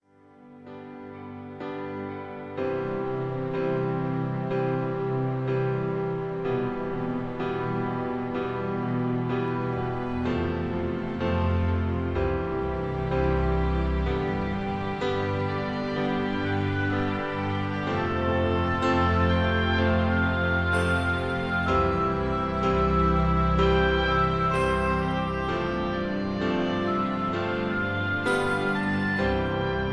(Key-C) Karaoke MP3 Backing Tracks